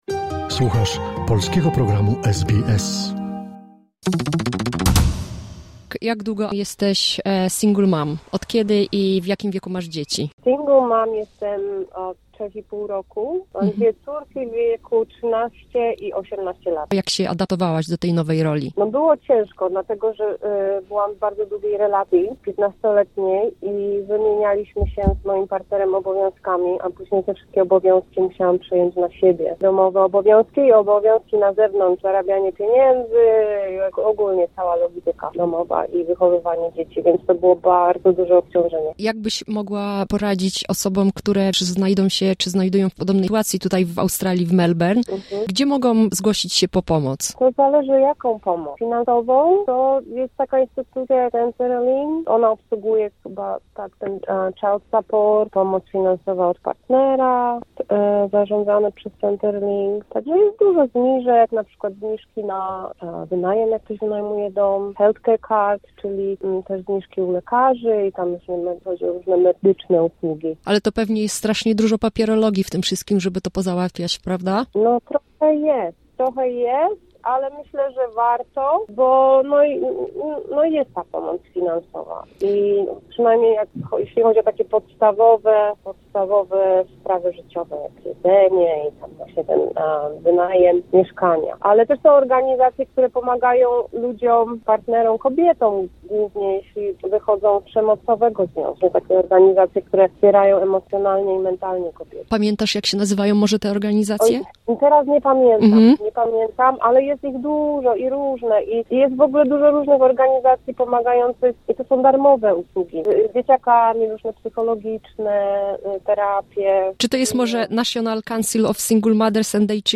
Wywiad z Polką, mieszkającą od wielu lat w Australii, matką dwojga dzieci, samotnie wychowującą swoje pociechy.